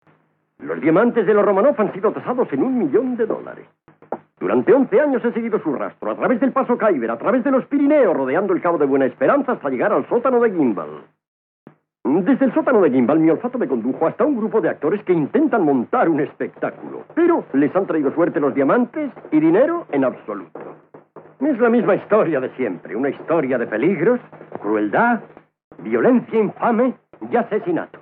También codificado en Dolby Digital 2.0 monoaural a 448 Kbps, el sonido doblado en castellano resulta notablemente peor que el de la versión original.
La distorsión es muy frecuente y los diálogos sufren un considerable ruido de fondo. La música, canciones y efectos de sonido procedentes de la pista original están en un estado mucho peor que su homónima, habiéndose recortado considerablemente la gama de frecuencias y añadido un alto grado de distorsión.
Aparentemente, el doblaje es un redoblaje de 1976, con Rafael de Penagos como Groucho.
muestra de audio del doblaje en castellano.